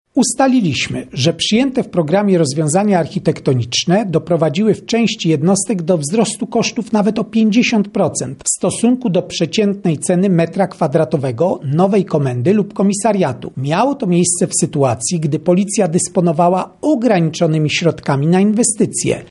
– mówi prezes NIK, Krzysztof Kwiatkowski.